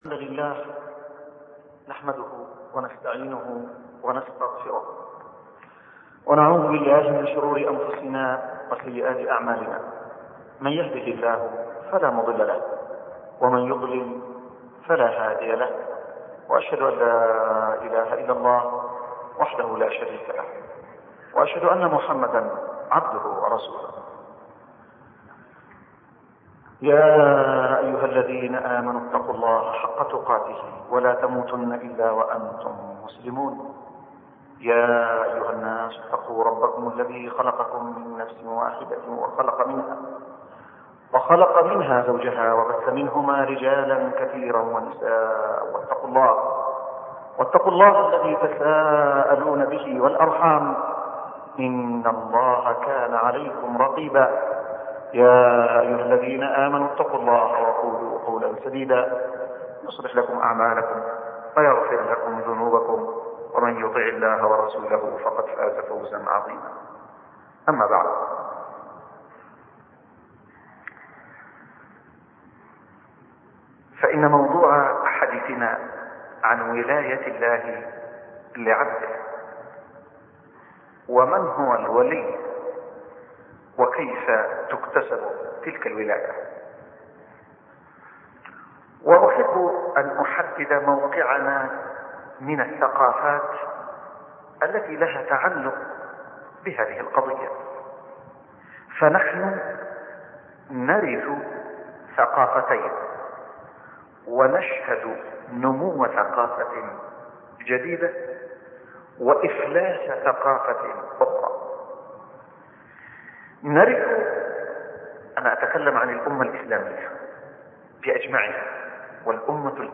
أرشيف الإسلام - ~ أرشيف صوتي لدروس وخطب ومحاضرات الشيخ عبد المجيد الزنداني